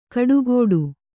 ♪ kaḍugōḍu